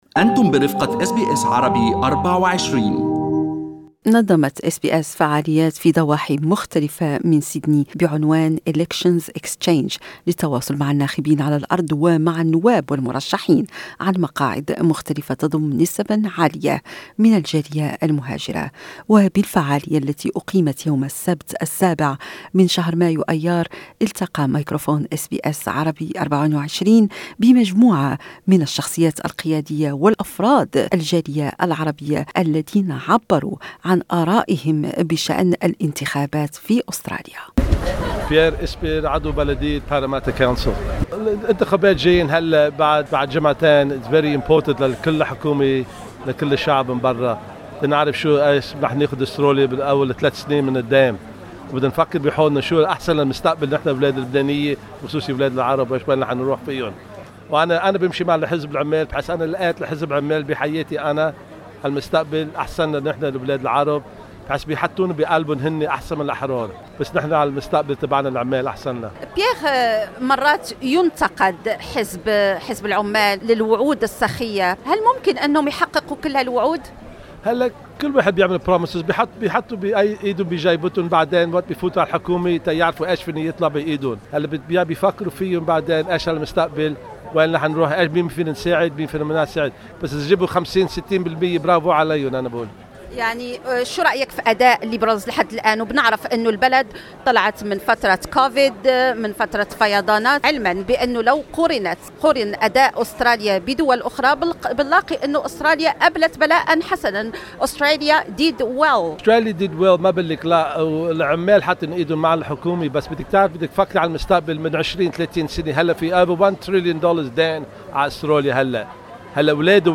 لقاء عن الانتخابات